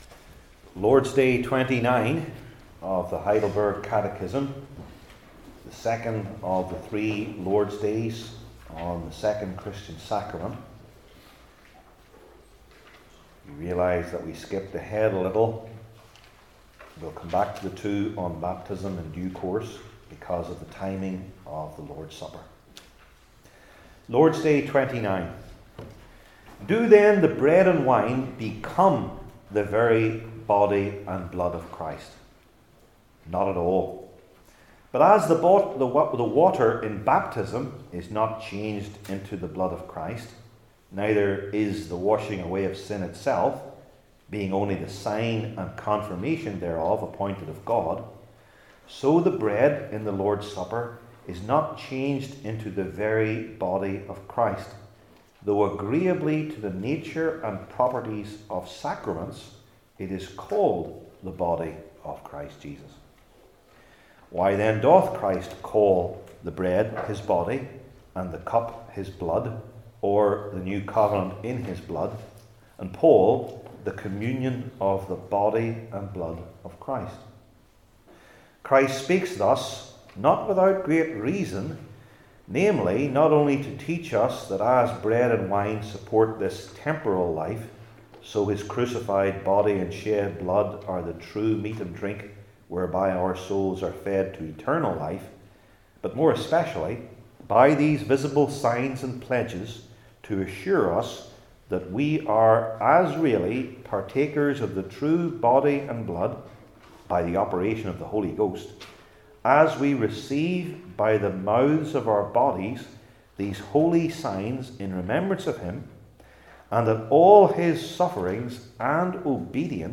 I Corinthians 11:17-34 Service Type: Heidelberg Catechism Sermons I. The True Meaning II.